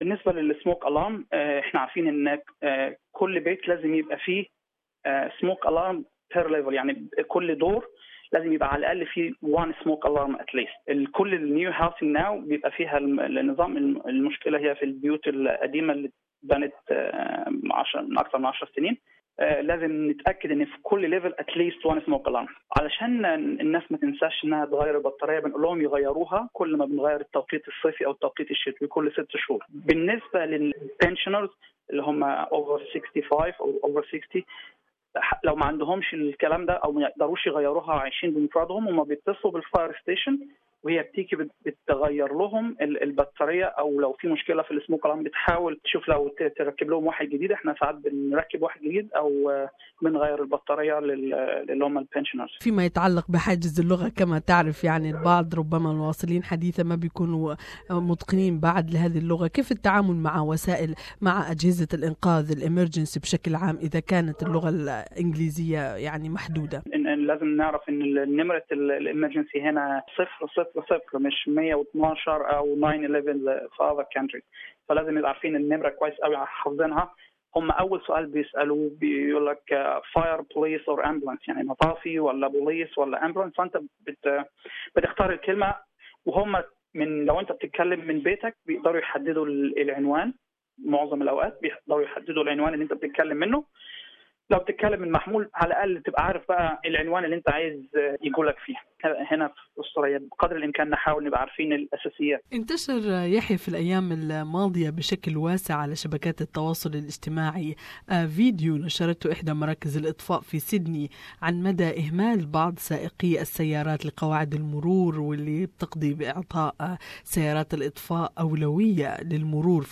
SBS Arabic